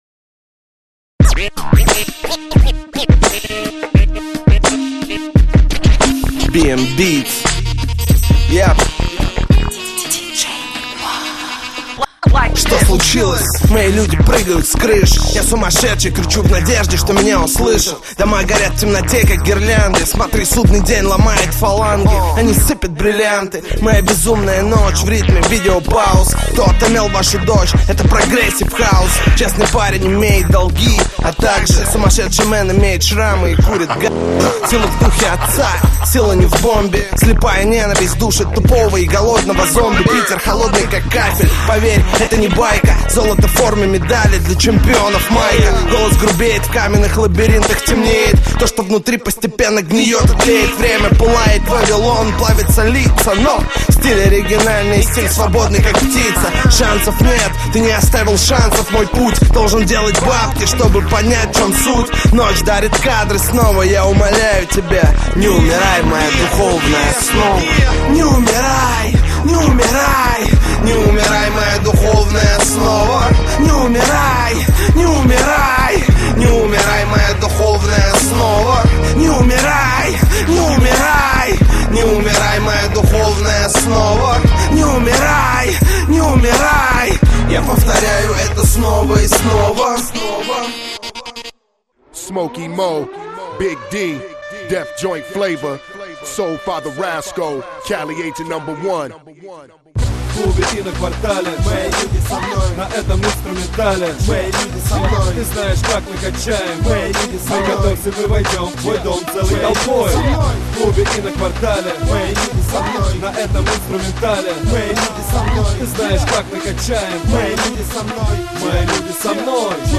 РэпЧина [631]